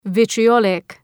Προφορά
{,vıtrı’ɒlık}